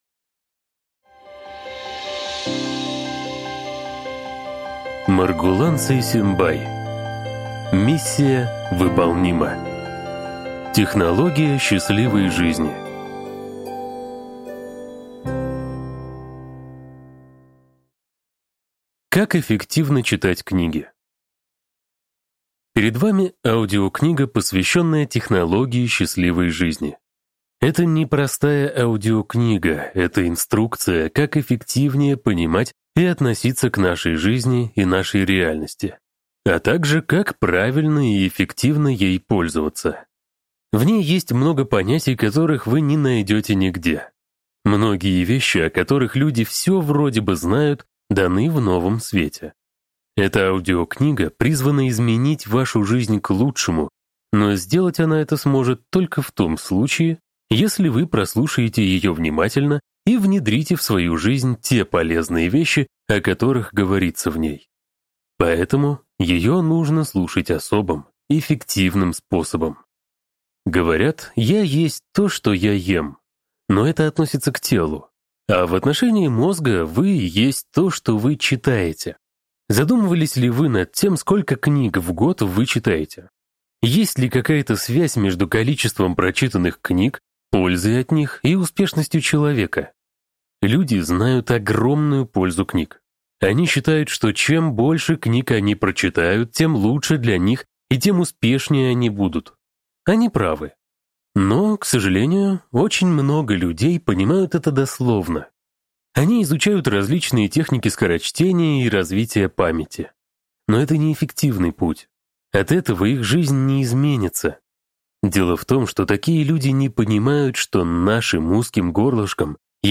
Аудиокнига Миссия выполнима. Технология счастливой жизни | Библиотека аудиокниг